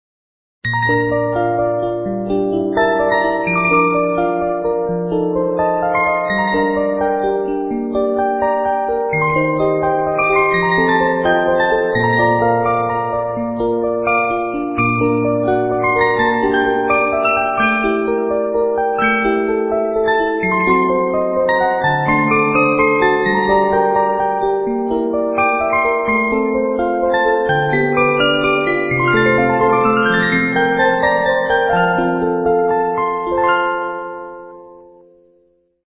Sound Bites of the Most Popular 72 Note Movements